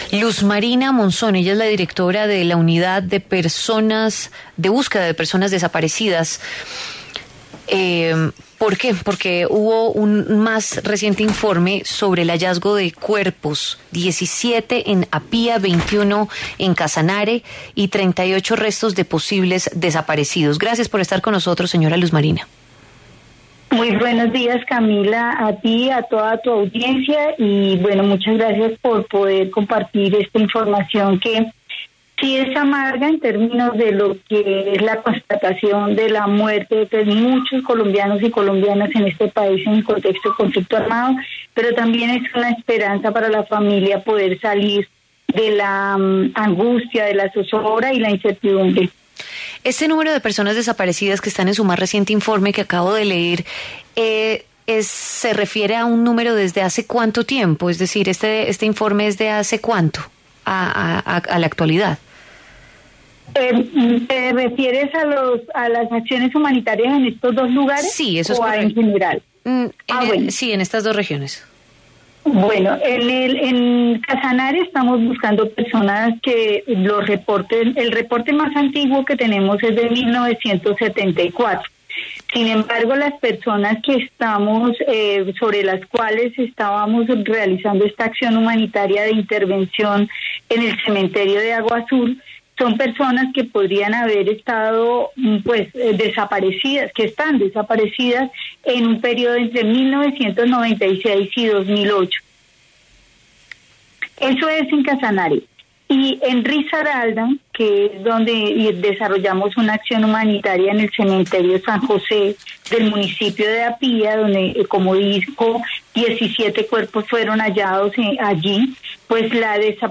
Luz Marina Monzón, directora de la Unidad de Búsqueda de Personas Dadas por Desaparecidas, habla en La W sobre el reconocimiento de las víctimas del conflicto armado.